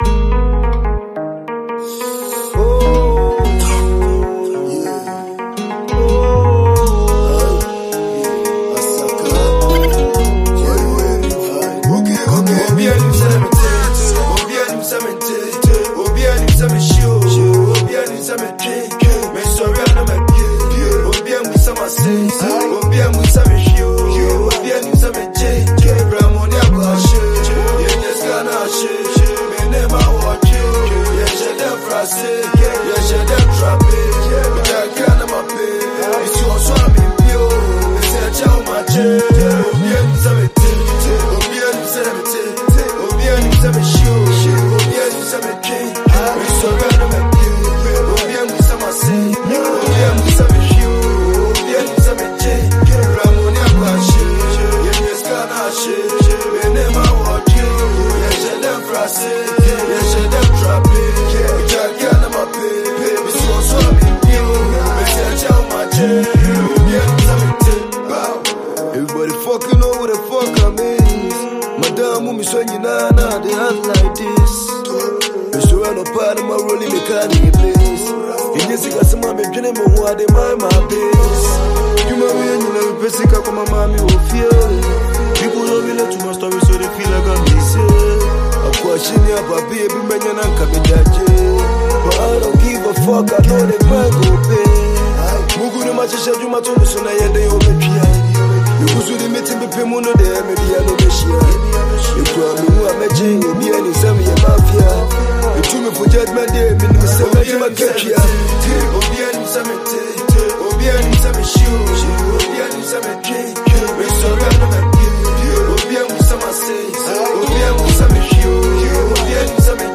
a Ghanaian Asakaa rapper
a brand new hip hop single.